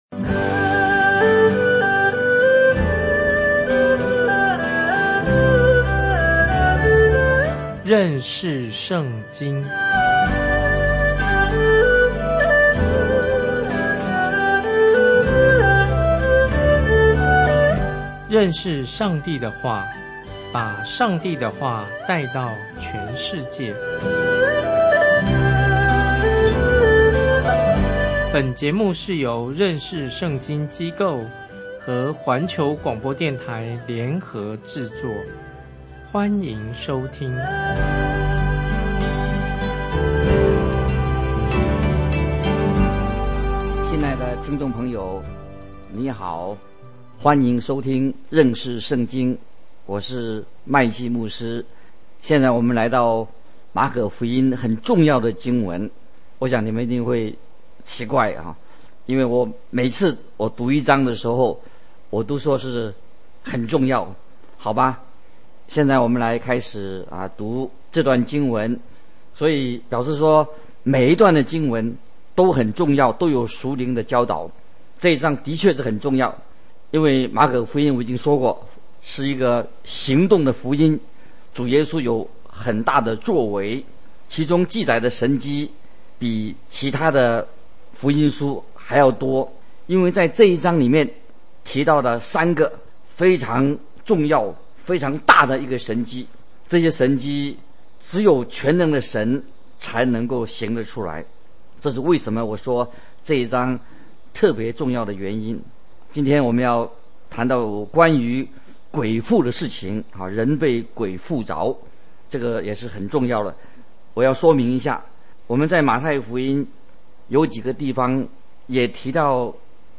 這是個每天30分鐘的廣播節目，旨在帶領聽眾有系統地查考整本聖經。